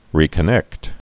(rēkə-nĕkt)